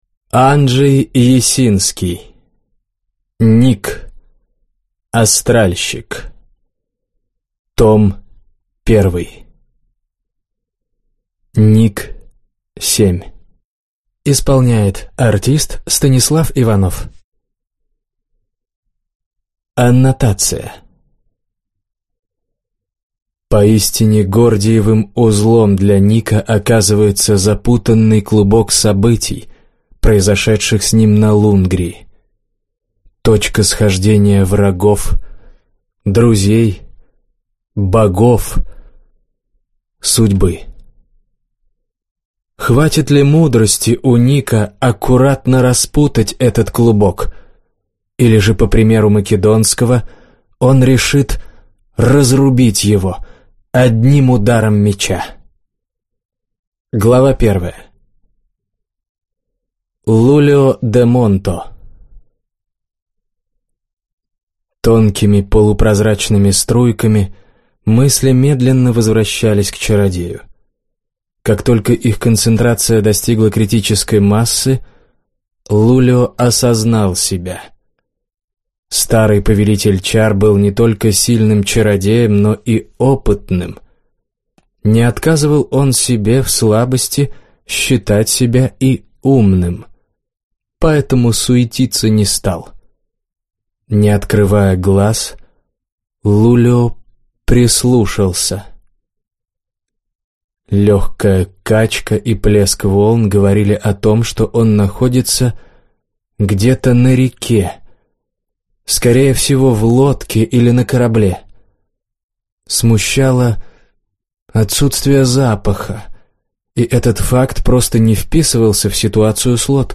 Аудиокнига Ник. Астральщик. Том 1 | Библиотека аудиокниг